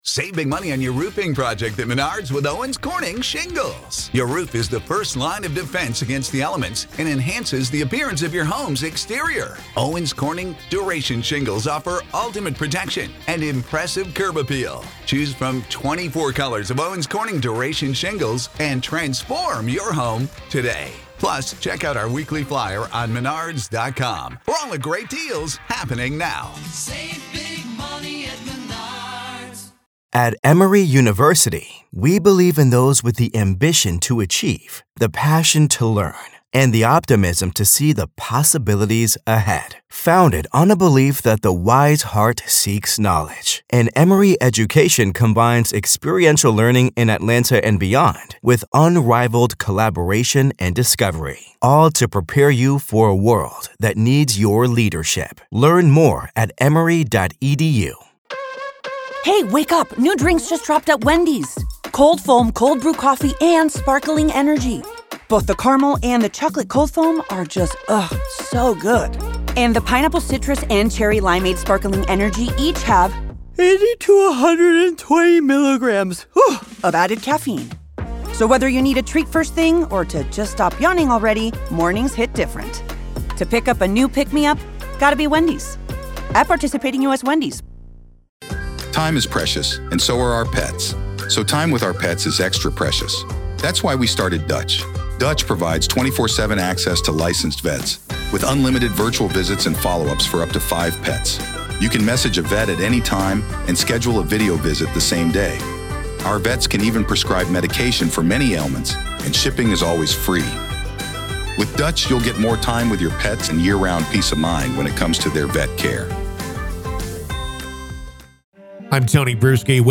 This is a retrospective look at the Alex Murdaugh case, as well as a riveting discussion that we've had with industry professionals over the course of the past few weeks.